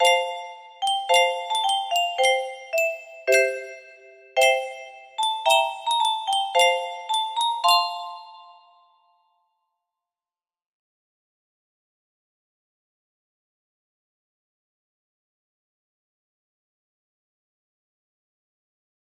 A drinking song about schuinsmarsjeerders.